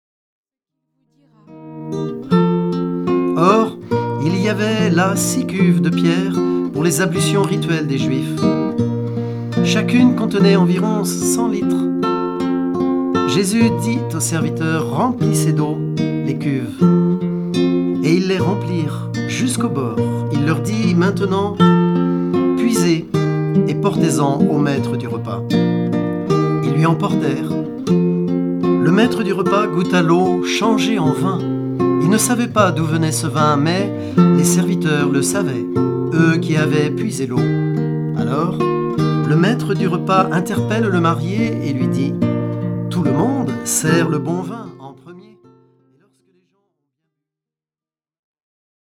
Chaque mystère est accompagné de chants pour la louange
Format :MP3 256Kbps Stéréo